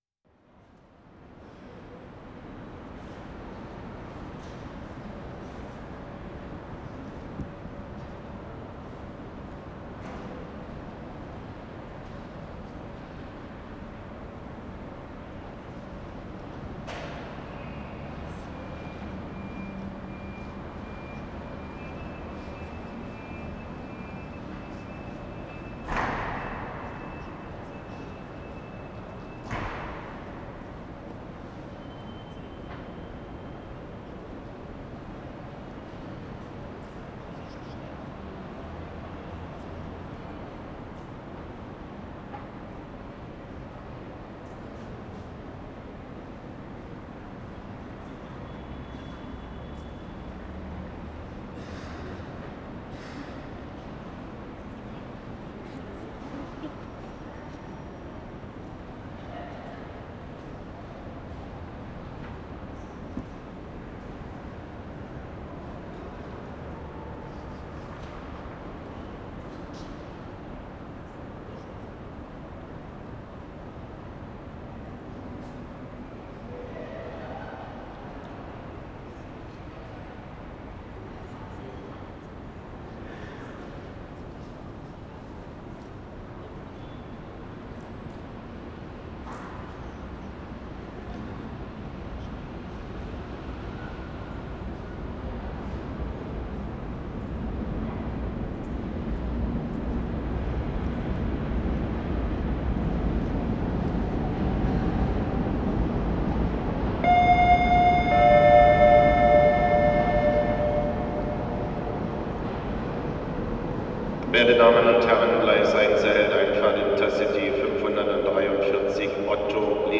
Zoologischer Garten Railway Station, platform (a more modern, rectangular roof construction, signals of closing doors of the ICE (InterCity Express) at 1:47, gong and announcement) 2:24